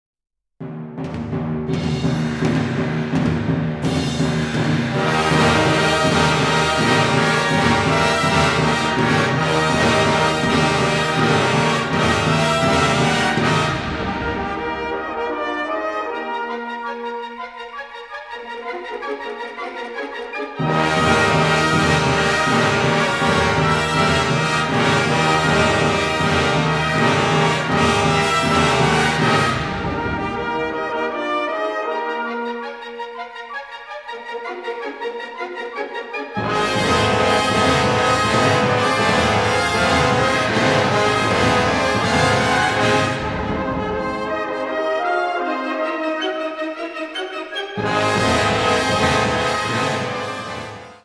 Kategorie Blasorchester/HaFaBra
Unterkategorie Zeitgenössische Bläsermusik (1945-heute)
Besetzung Ha (Blasorchester)